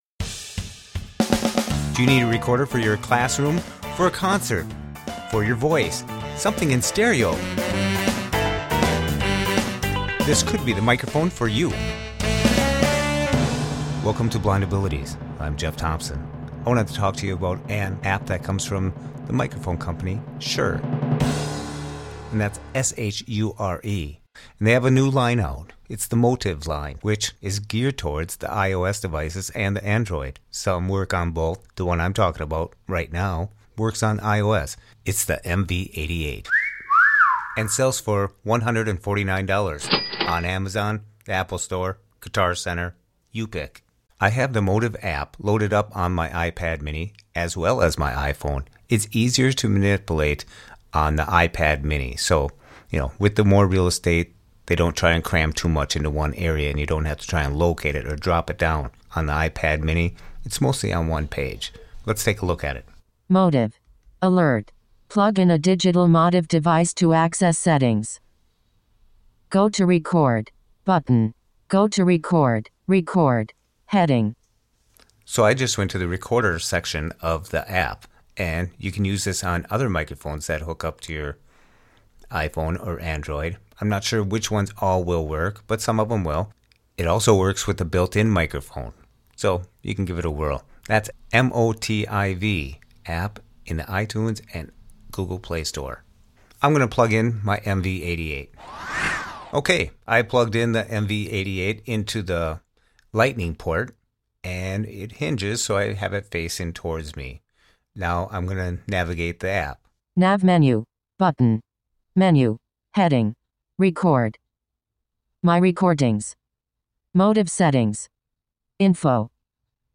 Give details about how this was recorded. With the MV88, anyone & everyone can benefit from the stereo quality recording.